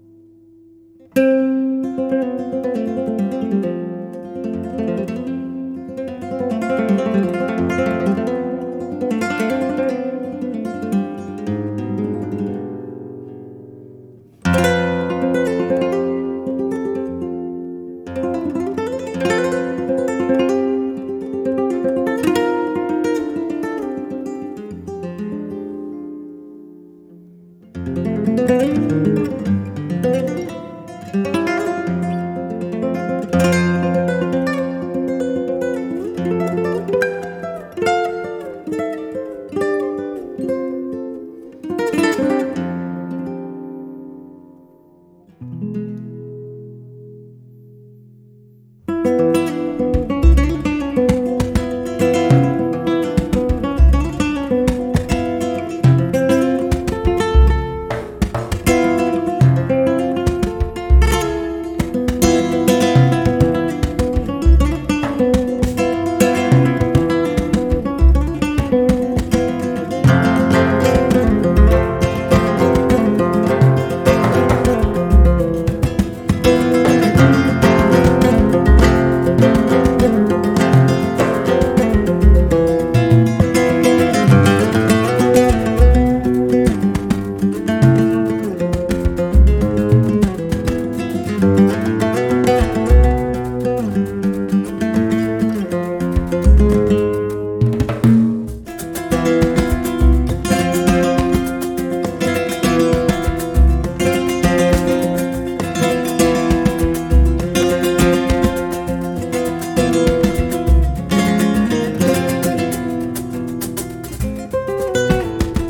Zurück zu: Flamenco
Tanguillos